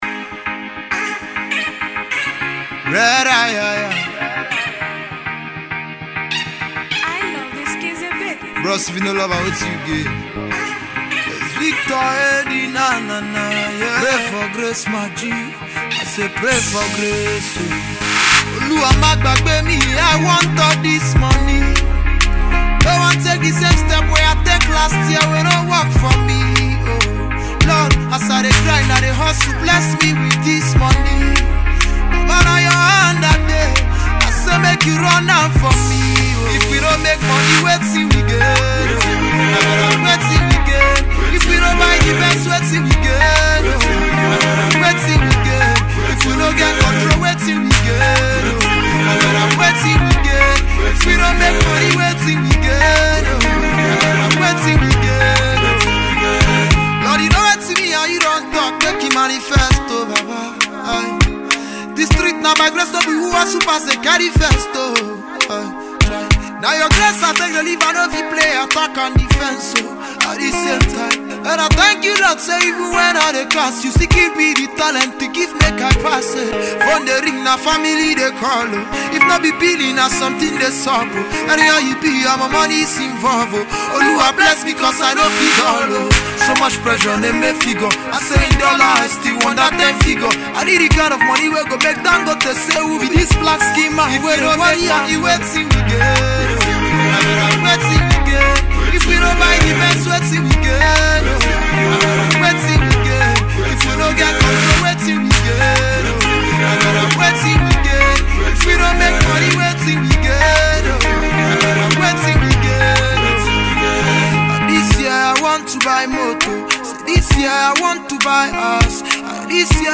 Stumbled on what appears to be a rap version